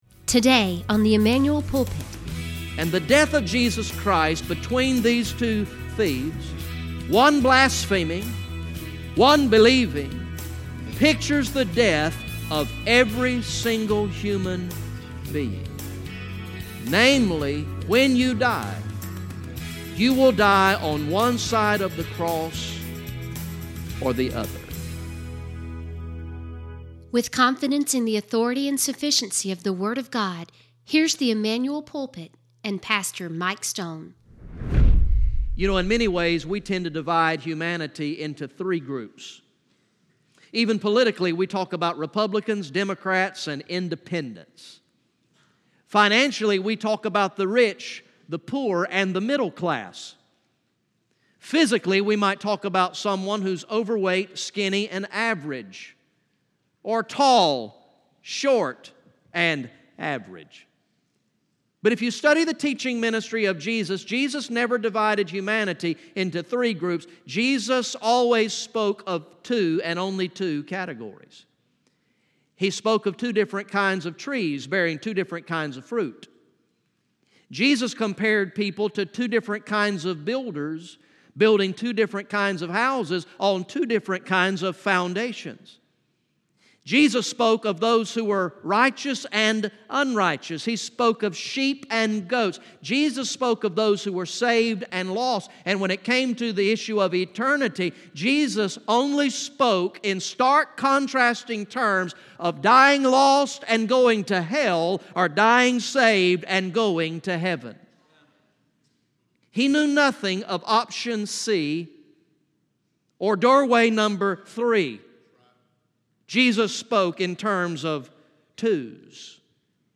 From the morning worship service on Sunday, October 14, 2019